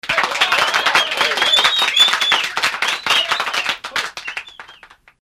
CLAP